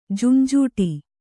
♪ junjūṭi